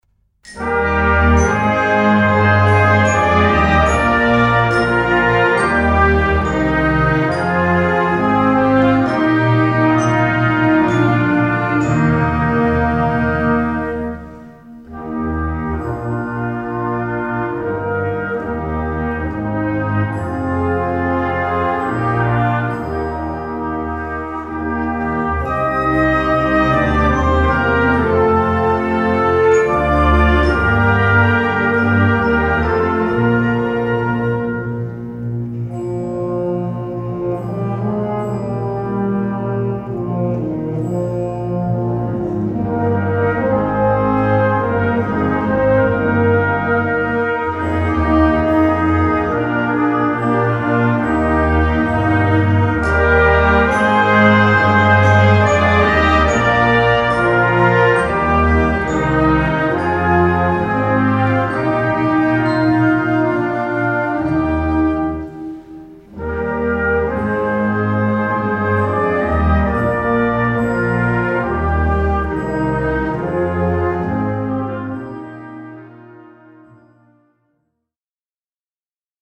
Gattung: Konzertwerk
2:30 Minuten Besetzung: Blasorchester PDF